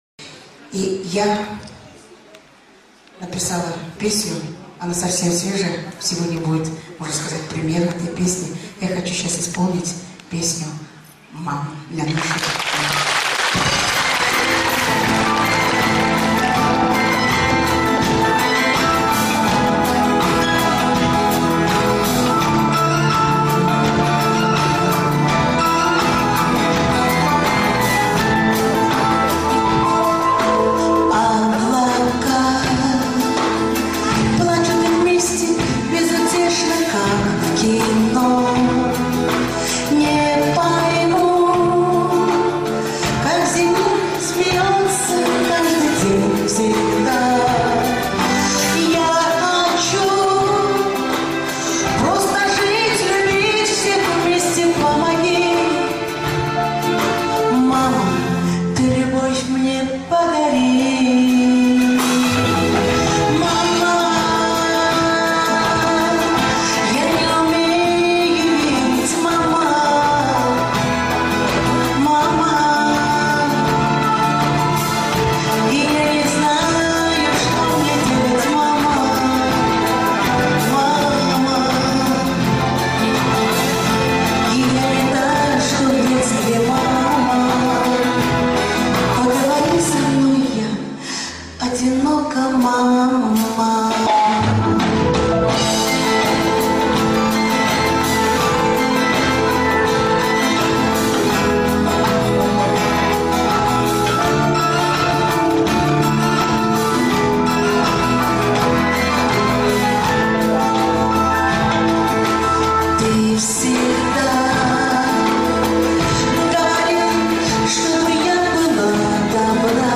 в живом исполнении